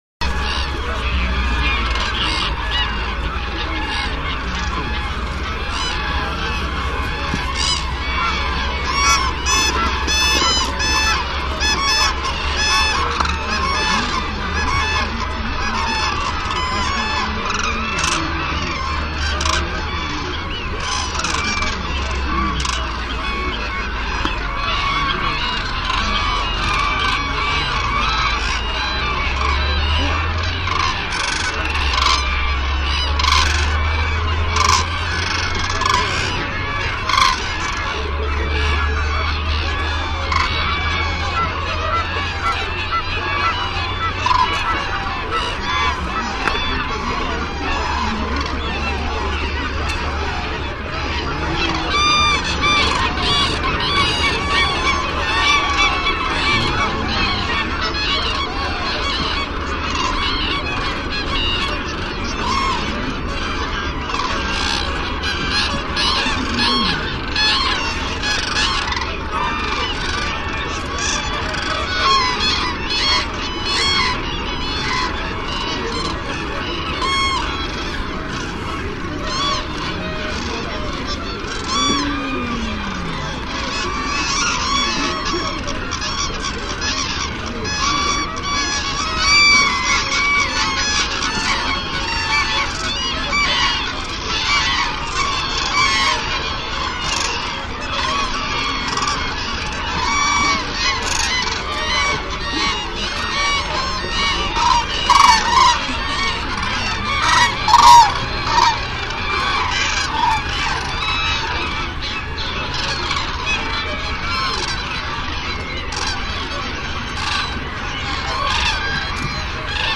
Traneparrets duetskrig kan i Danmark høres i april måned som et 2- eller 3-tonet hæst skrig.
Klik ovenfor og hør Tranens skrig.